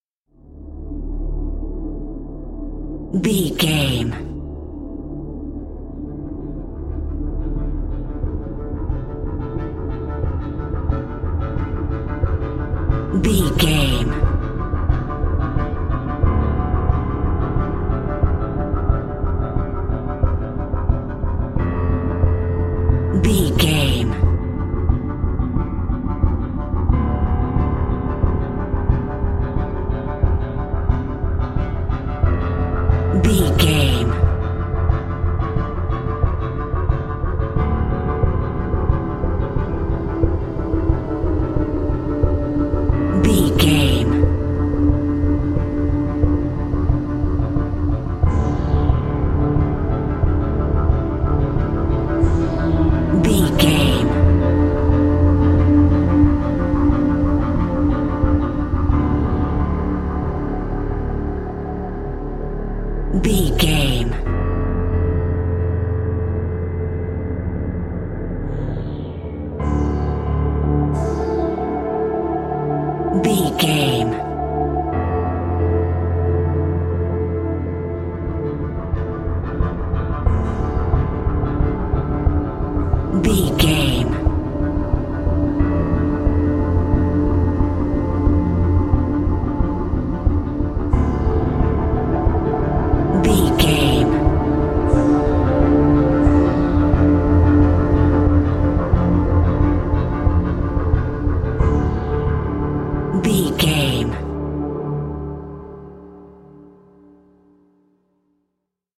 Music
Ionian/Major
Slow
piano
synthesiser
tension
ominous
dark
suspense
haunting
tense
creepy
spooky